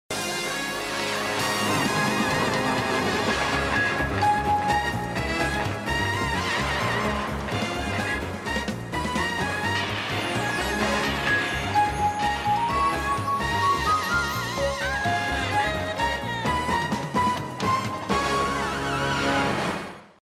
Здесь понапичканы одни и те же семплы, причем это 2000 год, явно какие-то АКАИ.